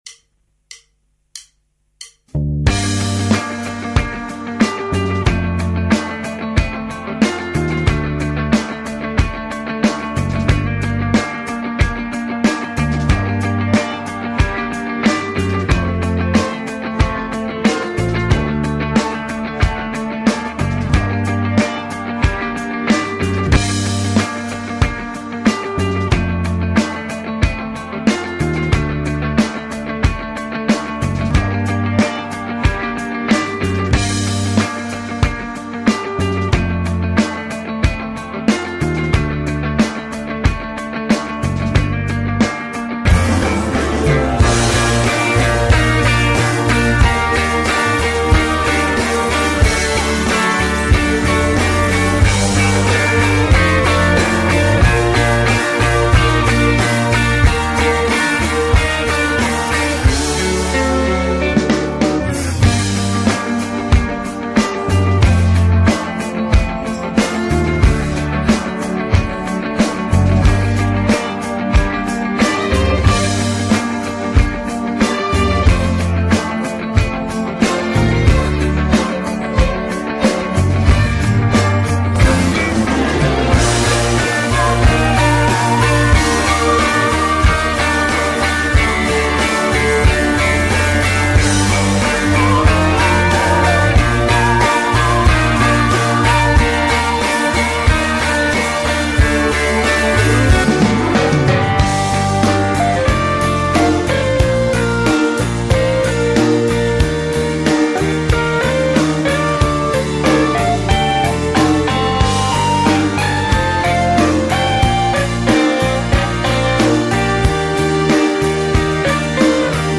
- фонограма -